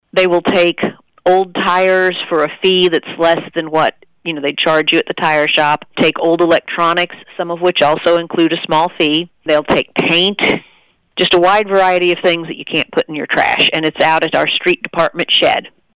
Outgoing Mayor Susie Bliss details what the event will accept from residents.